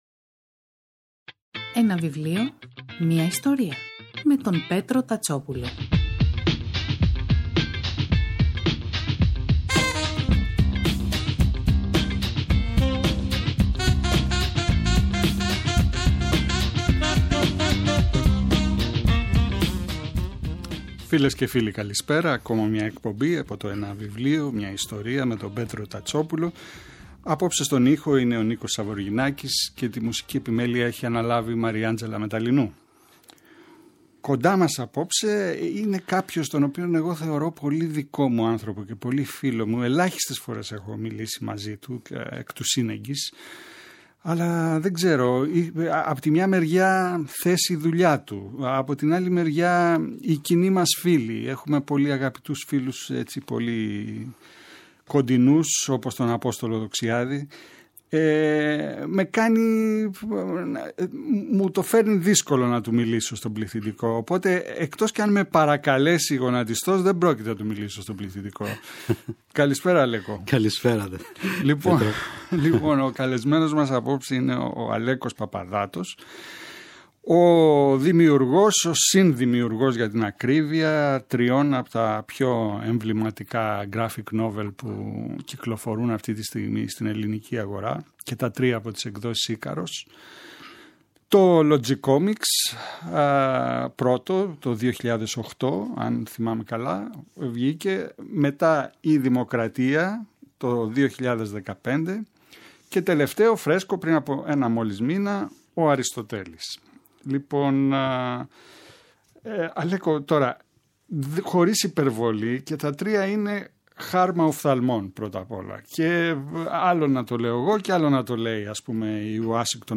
Το Σάββατο 29 Οκτωβρίου ο Αλέκος Παπαδάτος μιλά στον Πέτρο Τατσόπουλο για τα graphic novels “Logicomix” (2008), “Δημοκρατία” (2015) και “Αριστοτέλης” (2022) από τις εκδόσεις Ίκαρος.